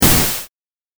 レトロゲーム （105件）
8bit消滅6.mp3